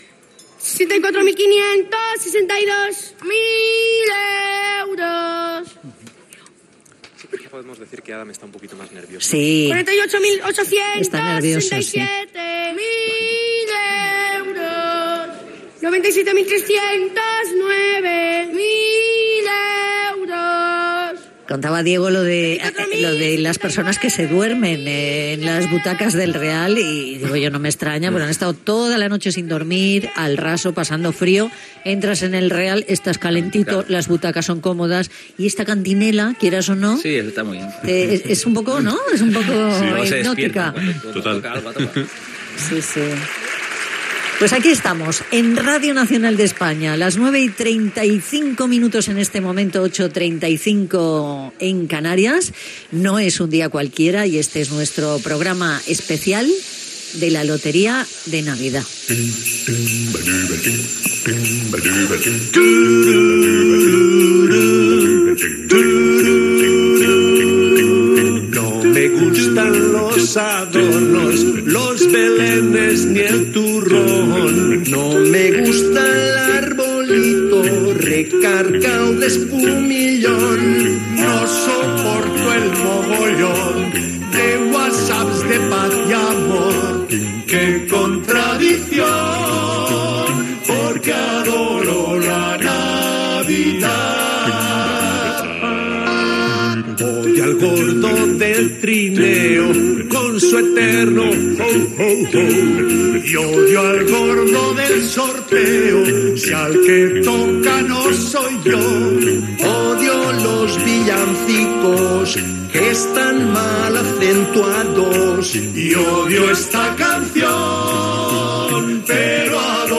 Transmissió del sorteig de la rifa de Nadal: cant dels números premiats
entrevista a un espectador que està al Teatro Real de Madrid veient el sorteig
nens que cantaran la següent taula dels premis